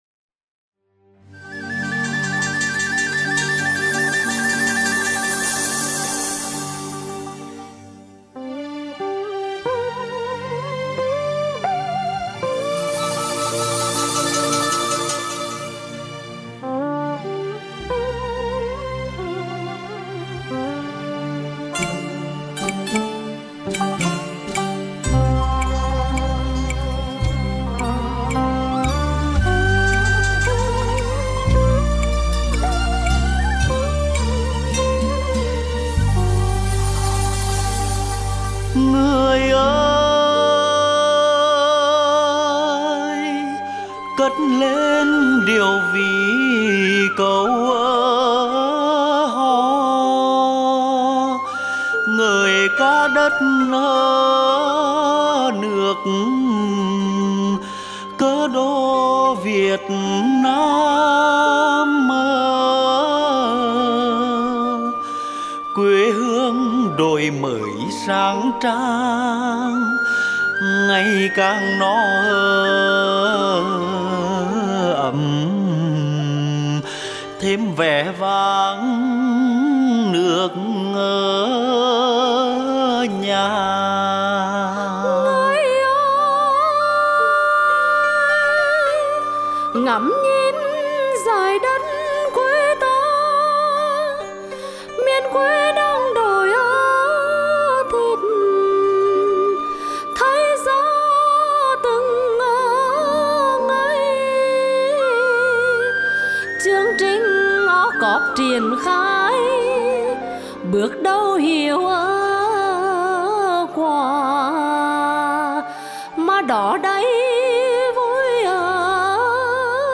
Tuyên truyền cải cách hành chính qua làn điệu dân ca